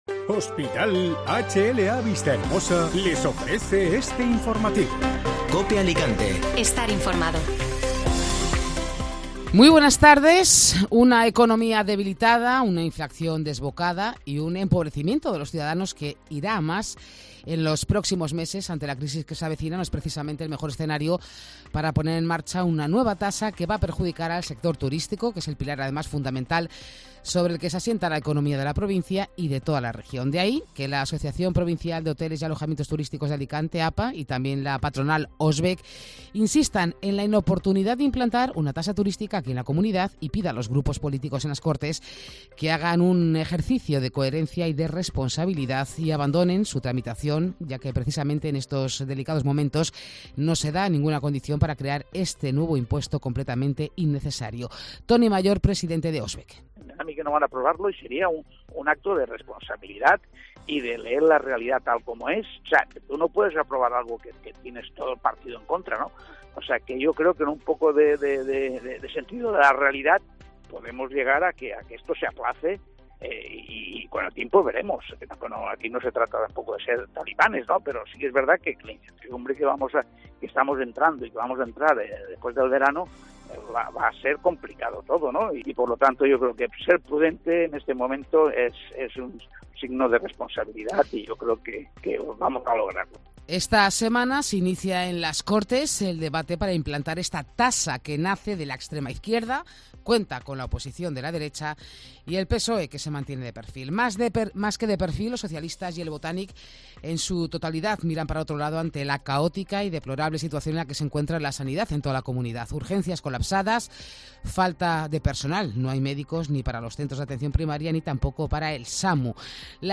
informativo Mediodía Cope (Lunes 11 de Julio)